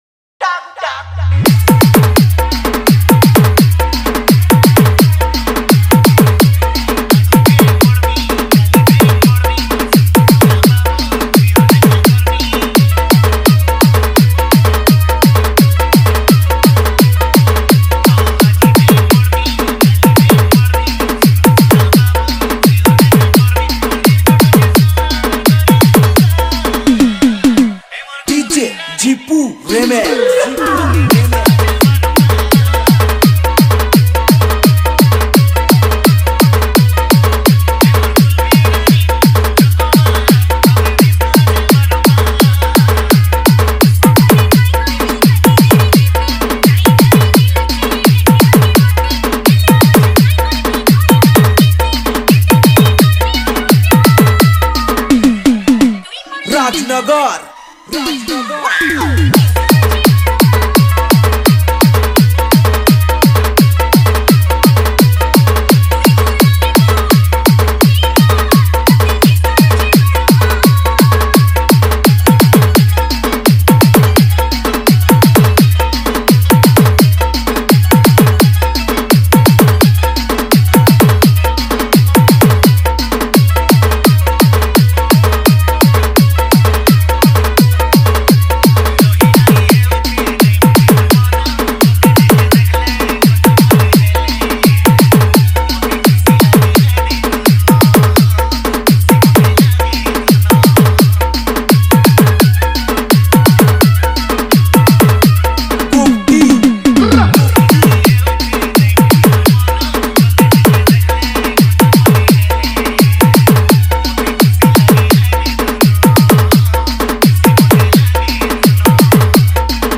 Sambalpuri Dj Song 2024
Category:  Sambalpuri Dj Song 2024